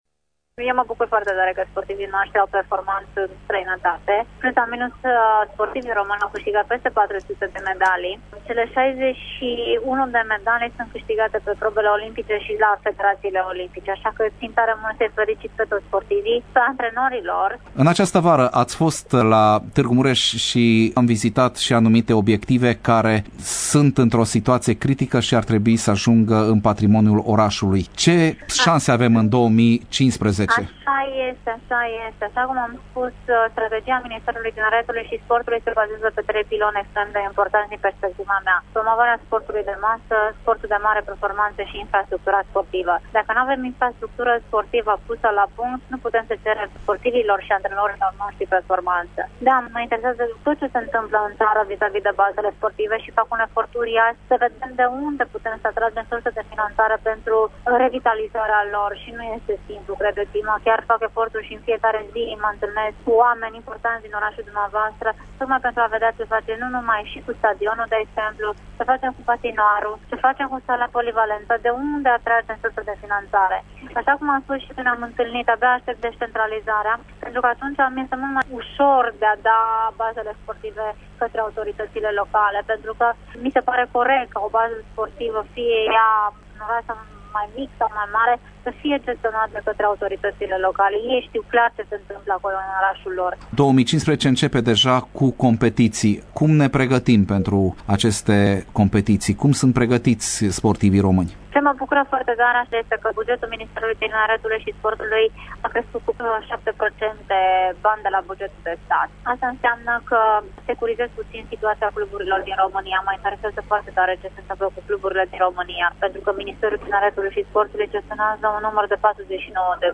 Invitată prin telefon în emisiunea Sens Unic, la Radio Tîrgu-Mureş, ministrul a vorbit despre planurile pentru 2015, care este şi an preolimpic, iar unul din scopurile Ministerului Sportului este să califice minimum 100 de sportivi la Jocurile Olimpice de la Rio, din 2016.
interviu-gabi-szabo.mp3